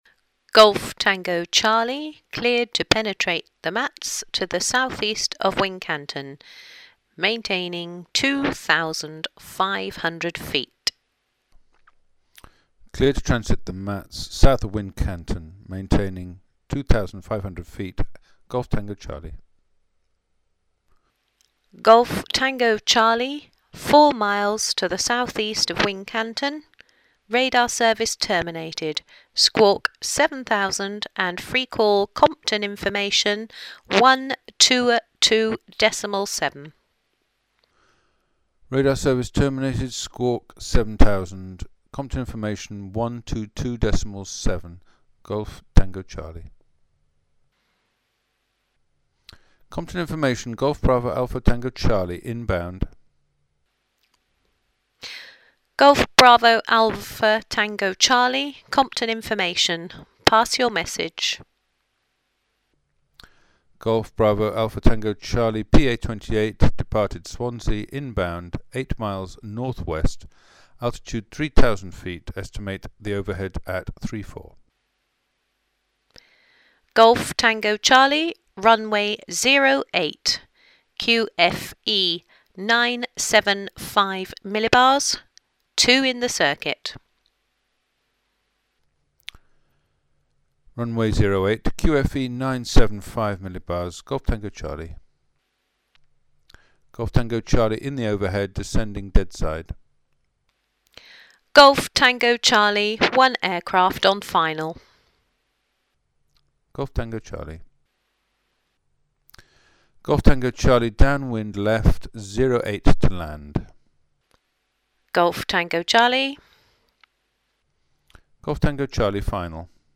Listen to the relevant exchanges between pilot and ground (links are in the text).